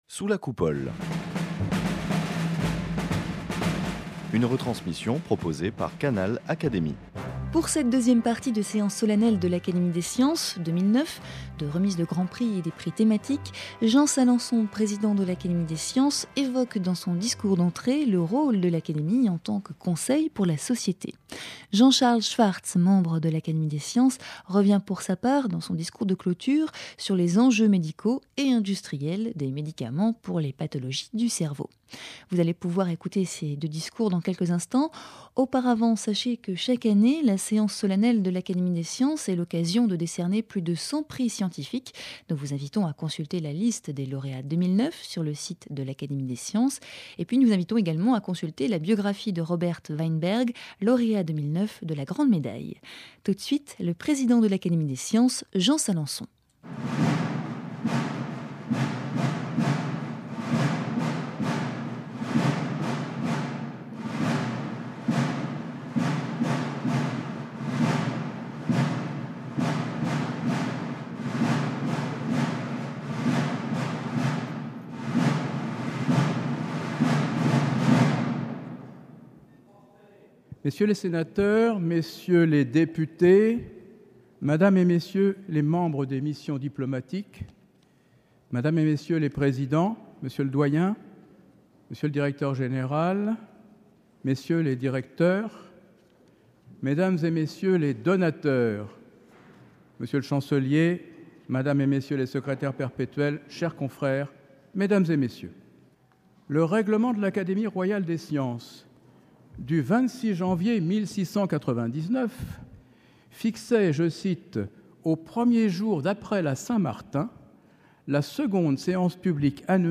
Deuxième partie de la séance solennelle de l’Académie des sciences, de remise des grands Prix et des Prix thématiques.